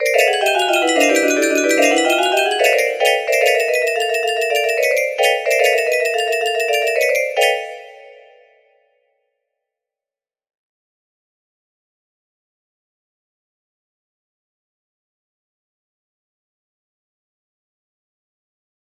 Cat and Mouse music box melody